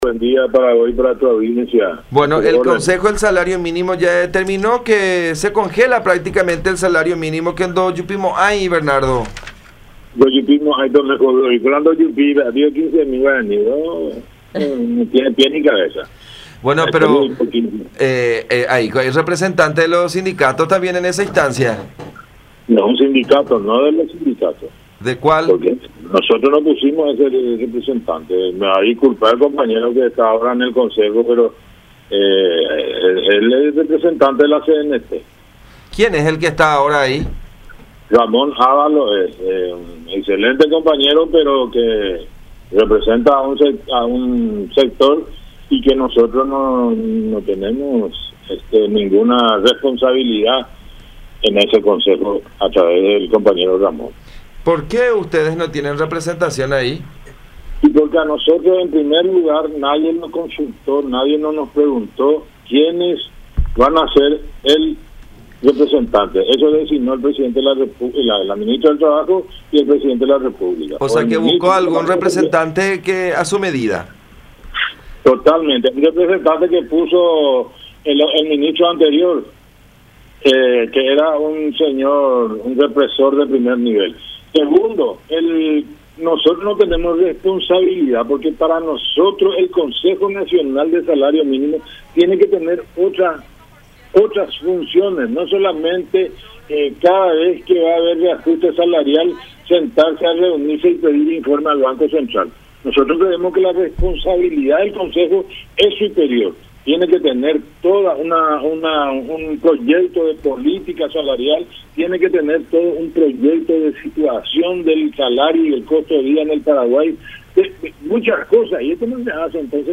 en diálogo con La Unión R800 AM.